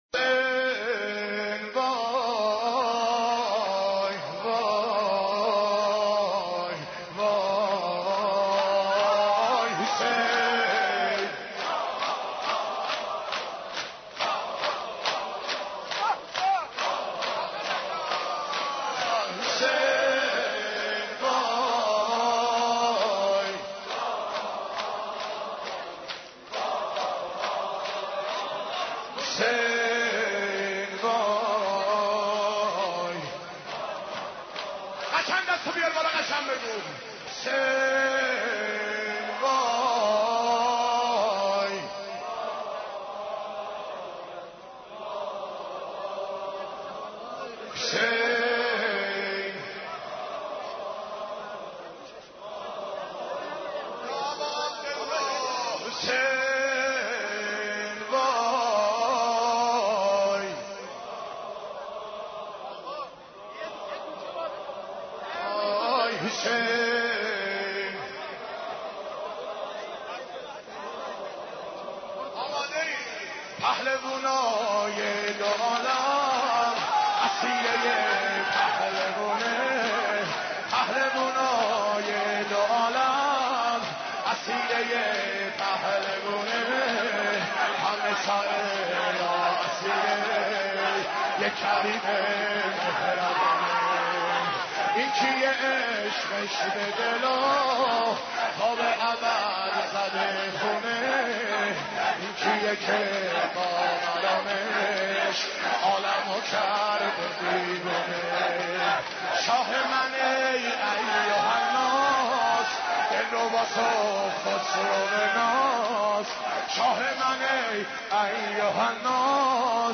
حضرت عباس ع ـ شور 17